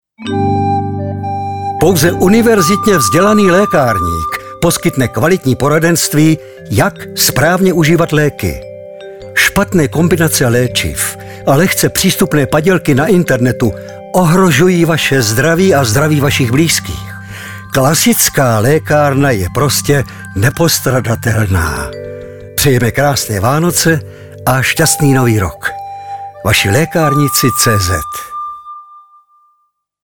Hlasem Vašich lékárníků je i nadále charismatický herec Ladislav Frej.